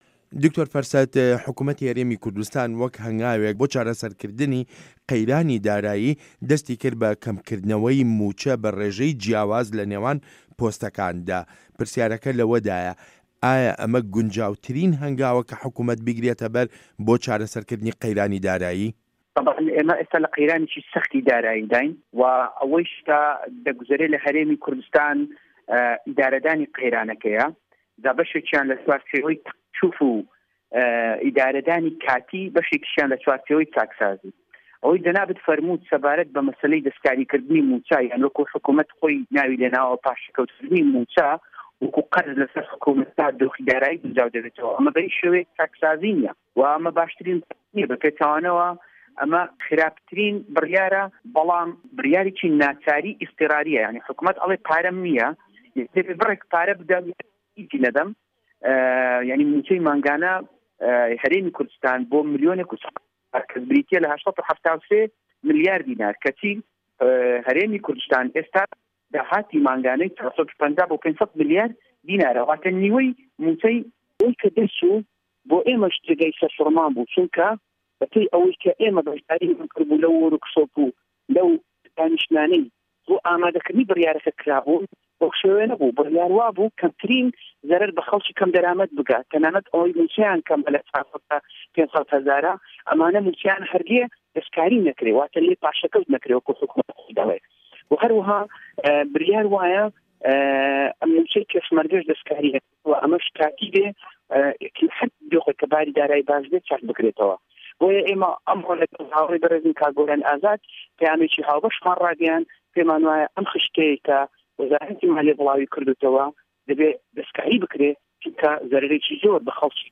وتووێژ لەگەڵ دکتۆر فەرسەت سۆفی